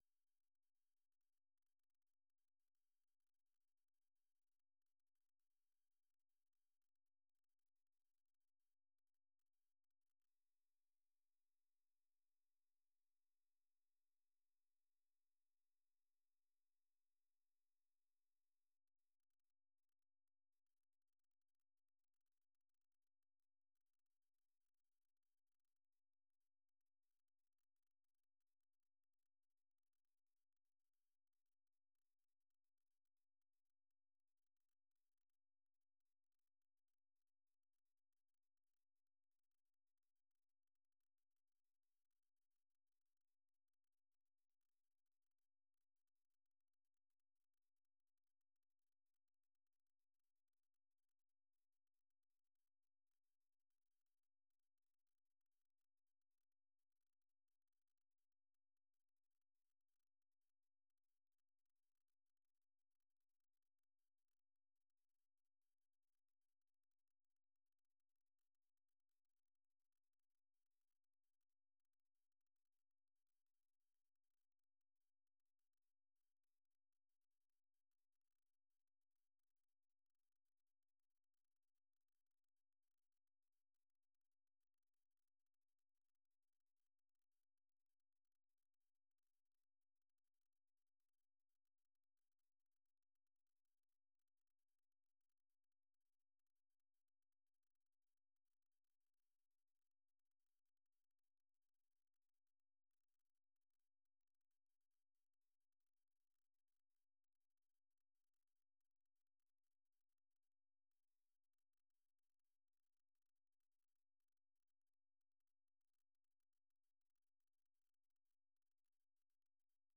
នេះជាកម្មវិធីផ្សាយប្រចាំថ្ងៃតាមវិទ្យុជាភាសាខ្មែរ រយៈពេល ៣០នាទី ដែលផ្តល់ព័ត៌មានអំពីប្រទេសកម្ពុជានិងពិភពលោក ក៏ដូចជាព័ត៌មានពិពណ៌នា ព័ត៌មានអត្ថាធិប្បាយ និងបទវិចារណកថា ជូនដល់អ្នកស្តាប់ភាសាខ្មែរនៅទូទាំងប្រទេសកម្ពុជា។